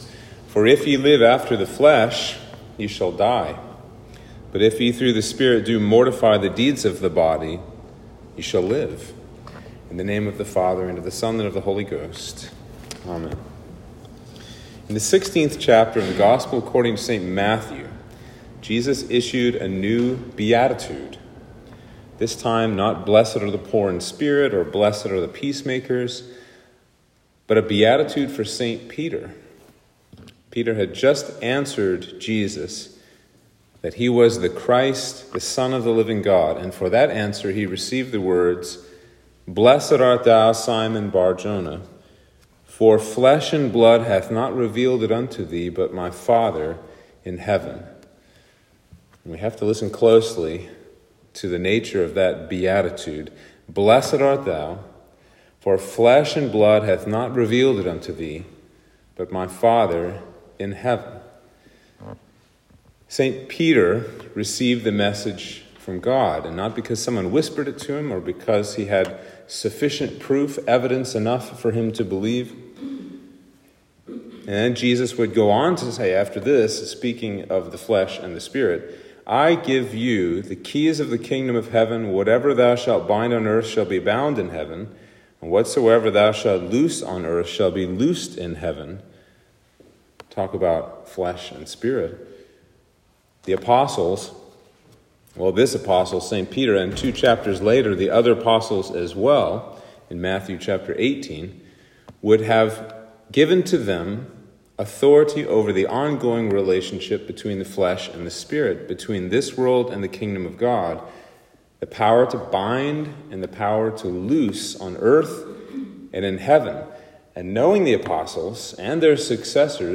Sermon for Trinity 8